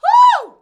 HUH.wav